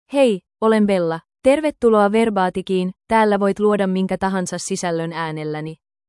Bella — Female Finnish AI voice
Bella is a female AI voice for Finnish (Finland).
Voice sample
Listen to Bella's female Finnish voice.
Female
Bella delivers clear pronunciation with authentic Finland Finnish intonation, making your content sound professionally produced.